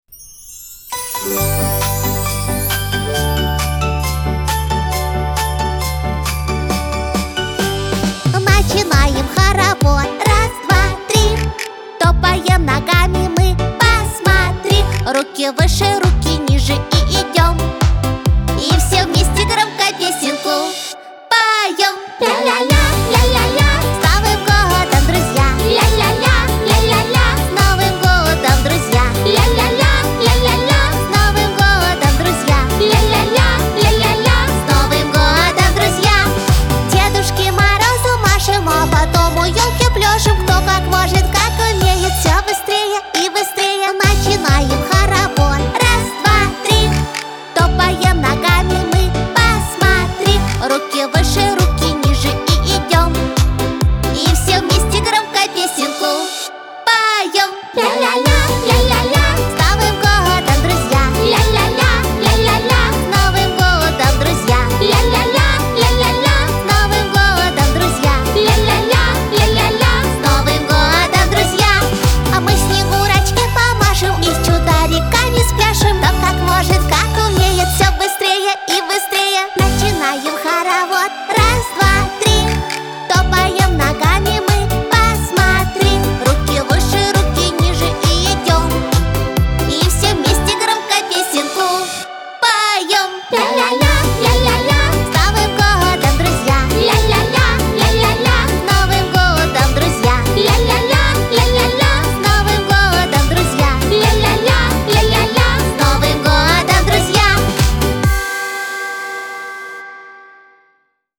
диско
весёлая музыка